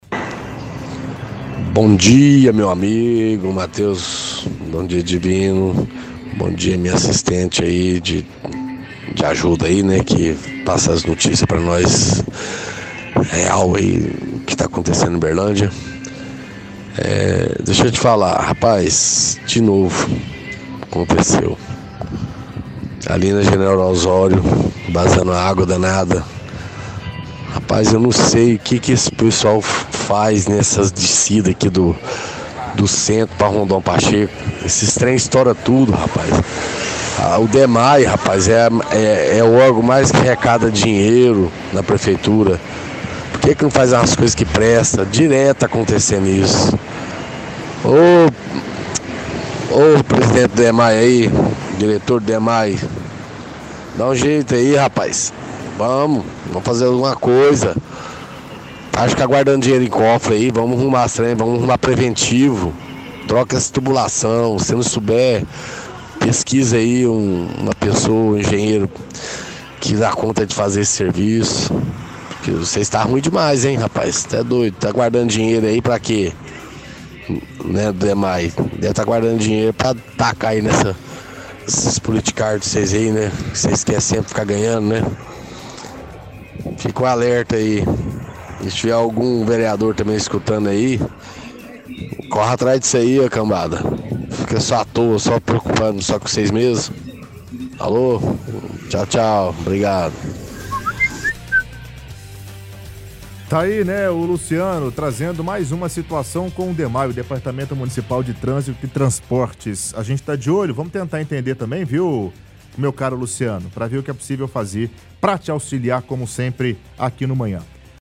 – Ouvinte reclama de vazamento na General Ozório.